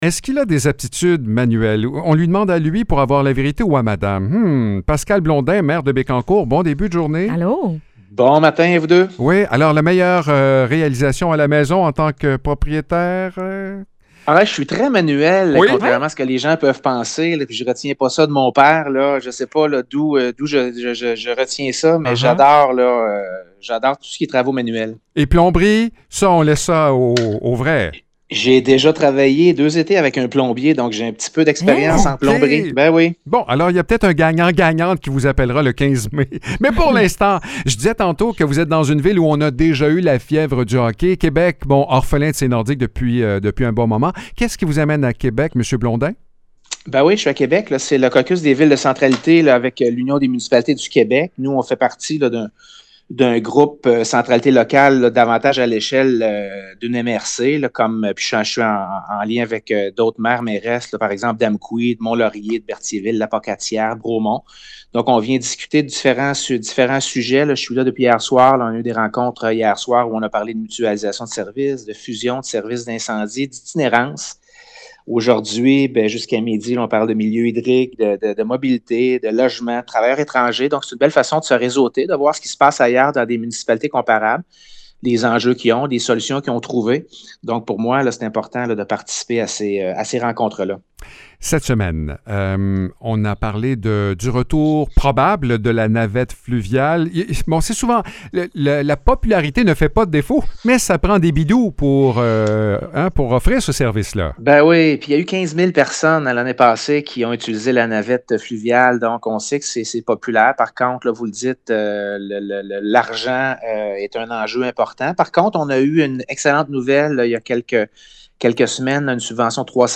Pascal Blondin, maire de Bécancour, fait le point sur deux dossiers importants pour les citoyens. Du côté de la navette fluviale, le projet continue de progresser, mais aucune annonce officielle n’a encore été confirmée. Bonne nouvelle toutefois pour le secteur de Sainte-Gertrude : les travaux se poursuivent afin d’améliorer la couverture du réseau cellulaire.